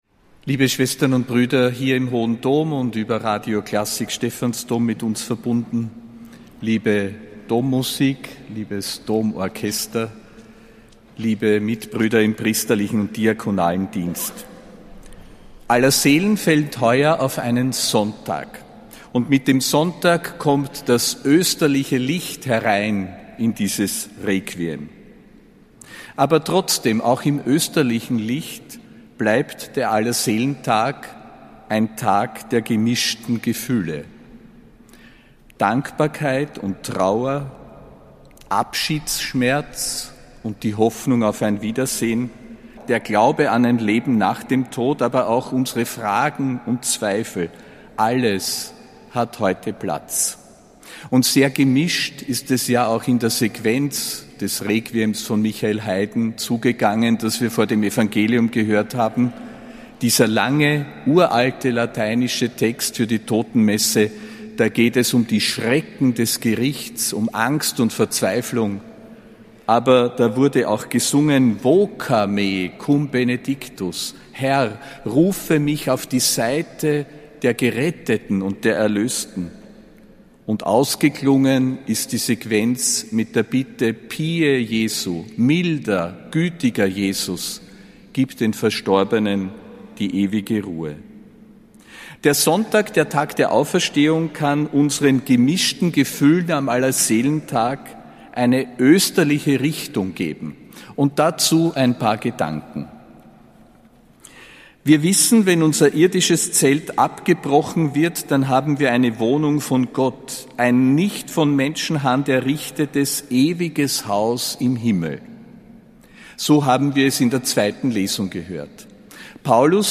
Predigt von Josef Grünwidl zu Allerseelen (November 2025).
Predigt des Ernannten Erzbischofs Josef Grünwidl zu Allerseelen, am